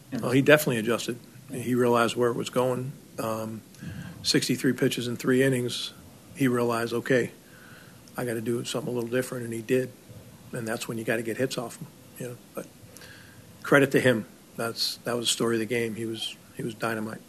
Murphy also talked about how Skenes adjusted to the Brewers lineup.